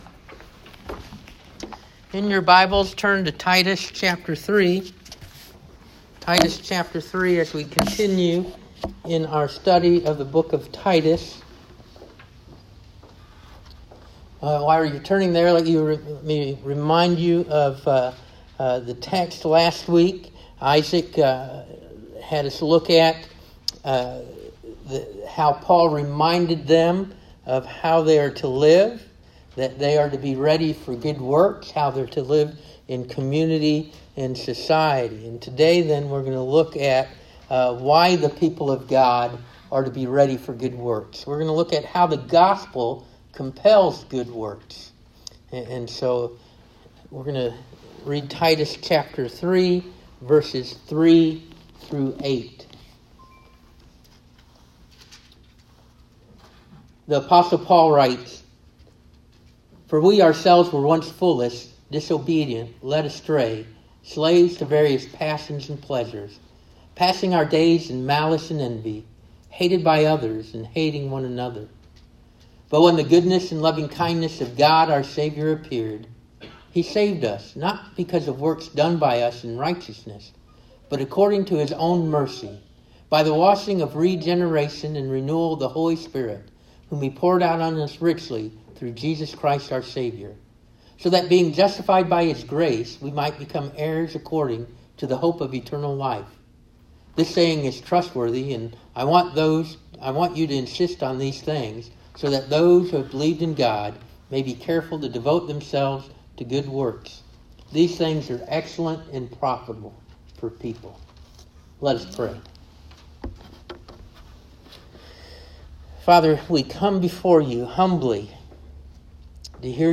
North Stafford Baptist Church Sermon Audio